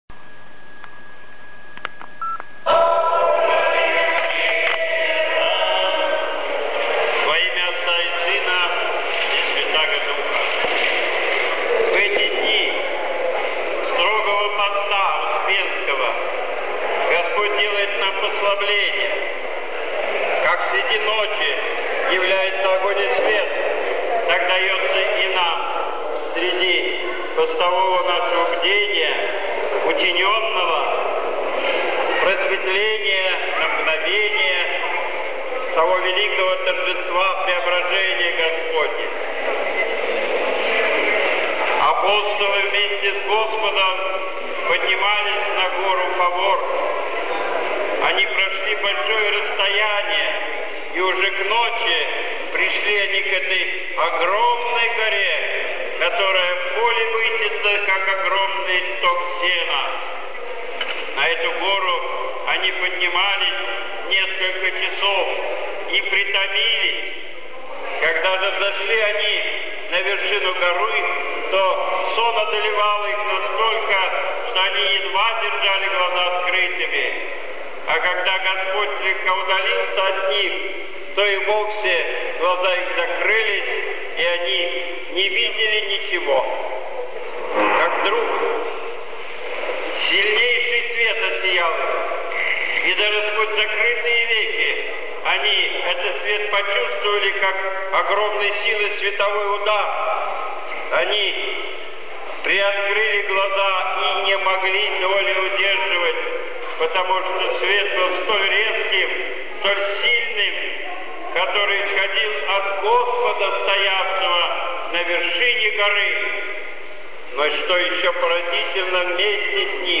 Слово на ПраздникПреображения Господня
Божественная Литургия 19 августа 2013 года